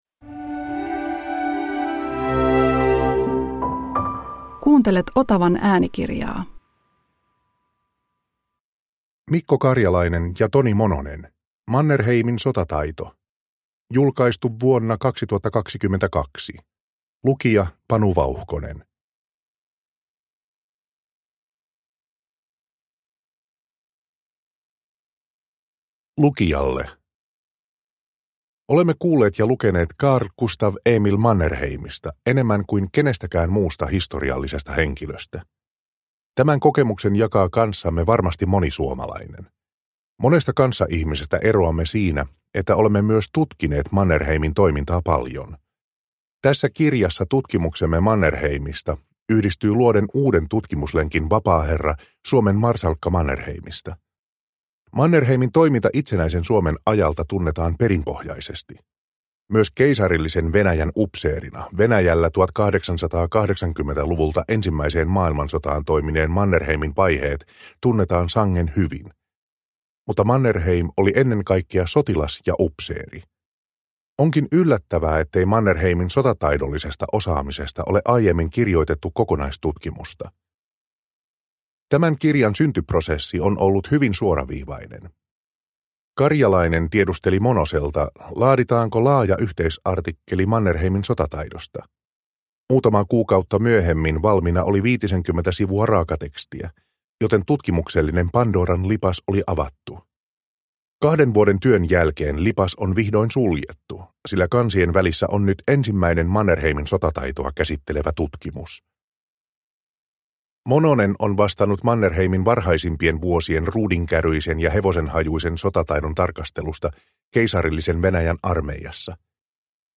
Mannerheimin sotataito – Ljudbok – Laddas ner